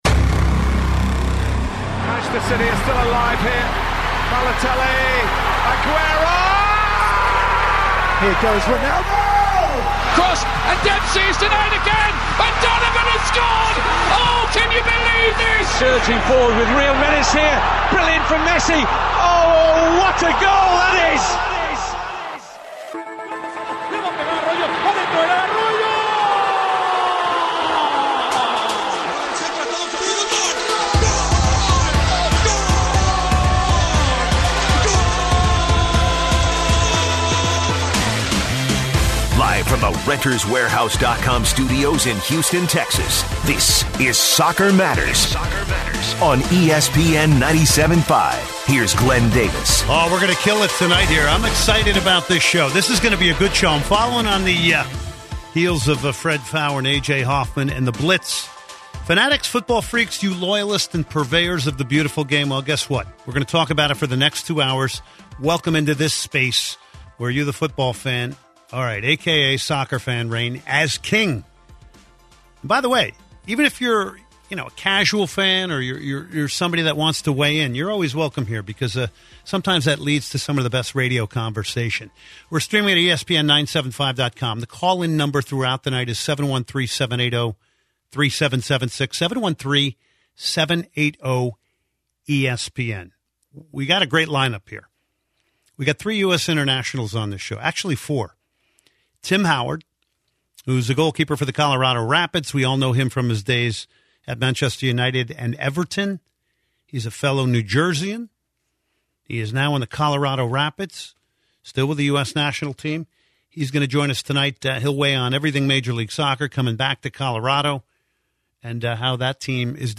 takes calls on the subject. Tim Howard, former USMNT Goalkeeper
Kasey Keller, Fox and ESPN analyst and former MLS goalkeeper